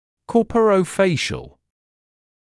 [ˌkɔːpərəu’feɪʃl][ˌкоːпэроу’фэйшл]телесно-лицевой